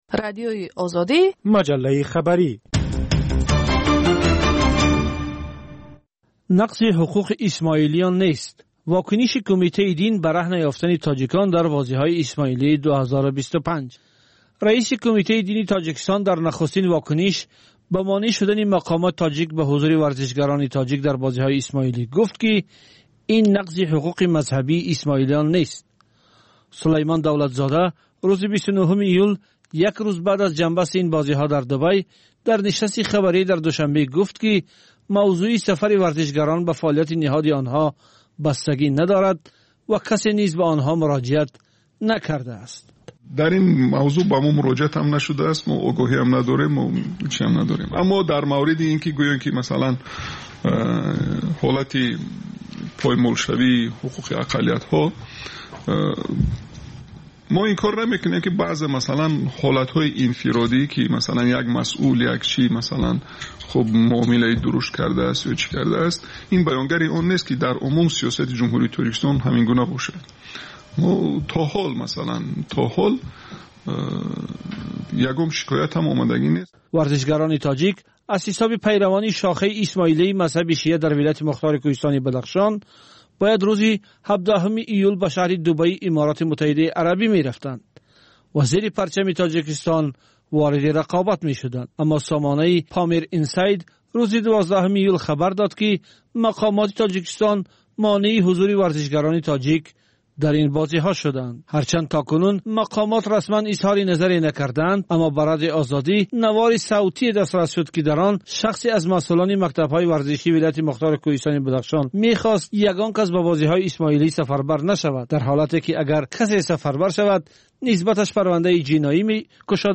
Тозатарин ахбори ҷаҳон, минтақа ва Тоҷикистон, таҳлилу баррасиҳо, мусоҳиба ва гузоришҳо аз масъалаҳои сиёсӣ, иҷтимоӣ, иқтисодӣ, фарҳангӣ ва зистмуҳитии Тоҷикистон.